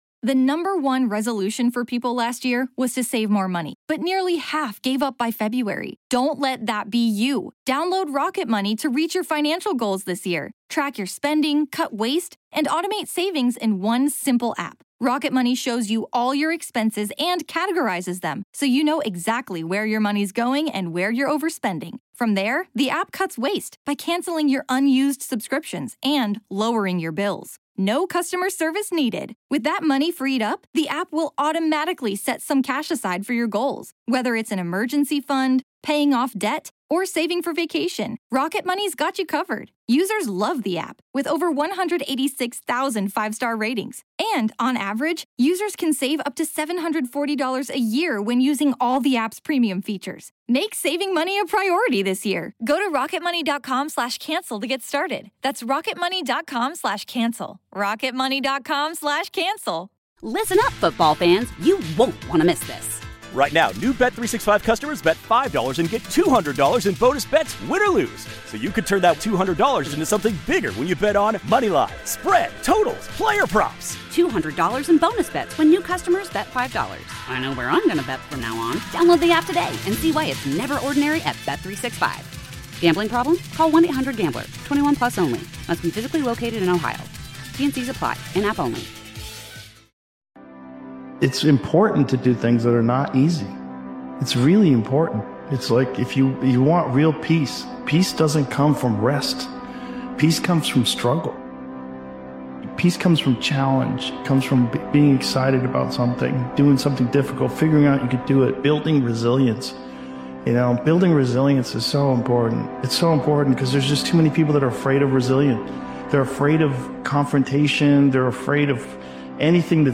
This powerful motivational speech compilation challenges you to rise above the ordinary patterns most people accept.